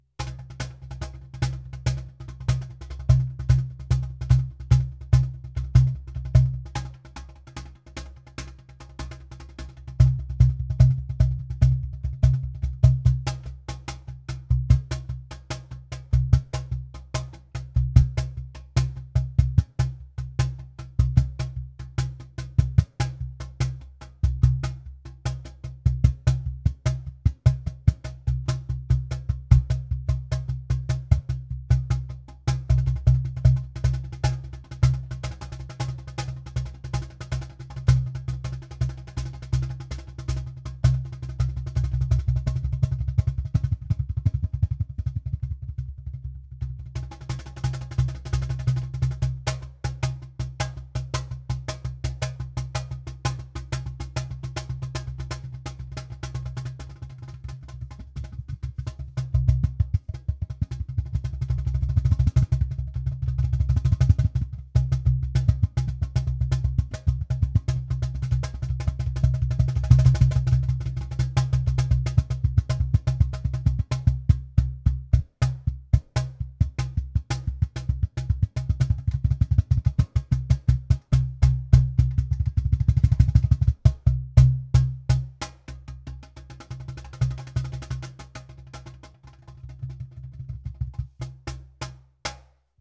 Back placement clip
With this placement you also get to hear the body sounds of the Cajon, including more lows. You can still hear some of the mids and highs.
cajon-sm57-back.m4a